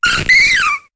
Cri de Joliflor dans Pokémon HOME.